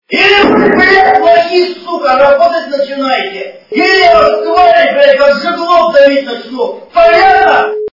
» Звуки » Люди фразы » Мужской Голос - Или вы сука, работать начинаете, или я вас блядь давить начну
При прослушивании Мужской Голос - Или вы сука, работать начинаете, или я вас блядь давить начну качество понижено и присутствуют гудки.